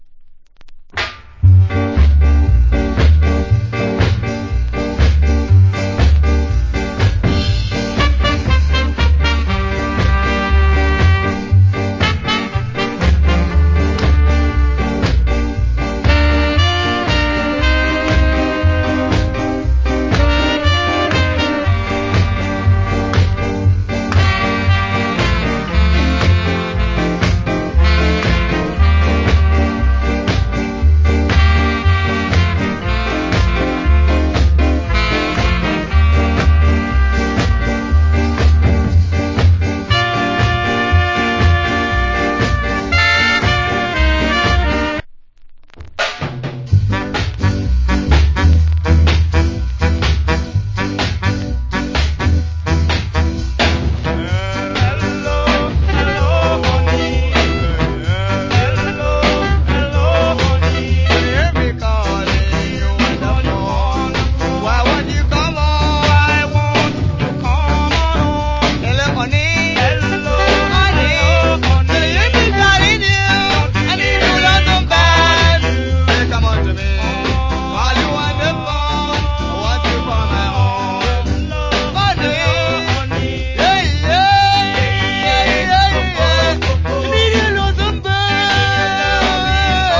Cool Ska Inst.